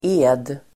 Uttal: [e:d]